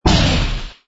engine_rh_fighter_kill.wav